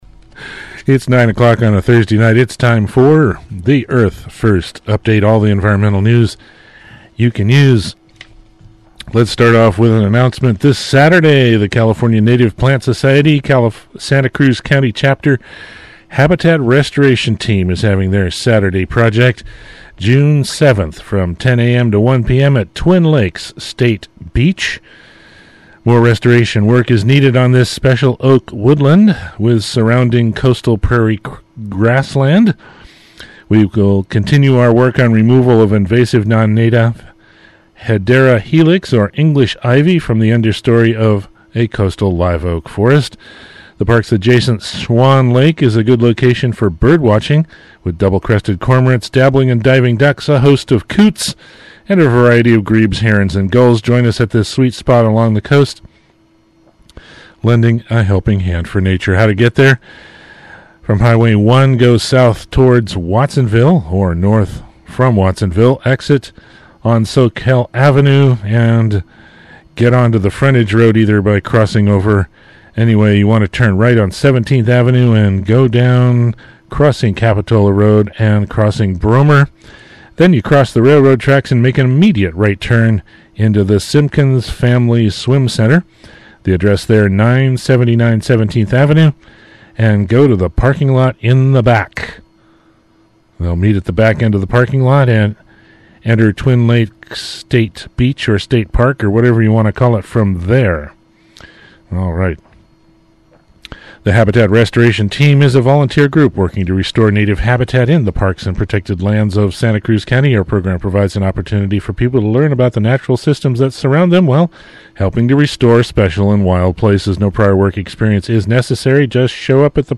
Earth First! Radio News is a No Compromise weekly radio program produced at Free Radio Santa Cruz. Focussing on Direct-Action and Bio-diversity, EF! Radio provides Eco-action and activist news, calls-to-action, updates and commentary on current events worldwide.
Earth First! Radio is broadcast live from Free Radio Santa Cruz 101.1 FM, Thursday at 9:00 pm.